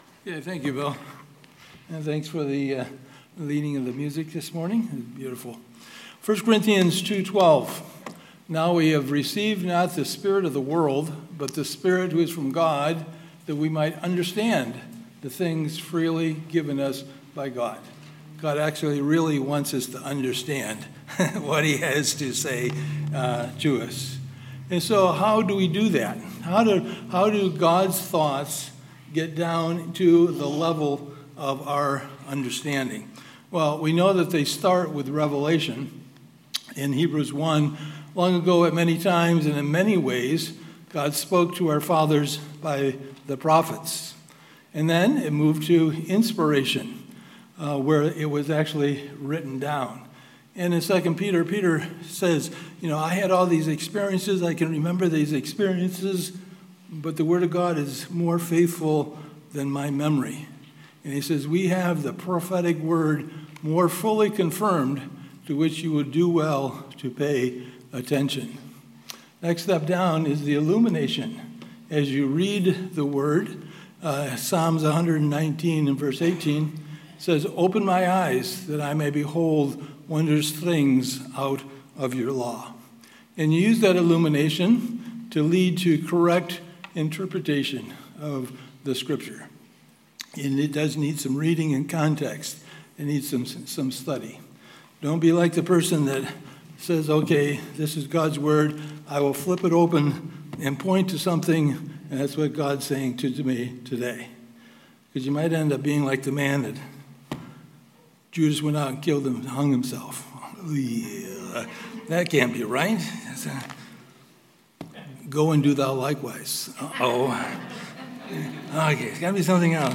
Sermons | Ellington Baptist Church
Guest Speaker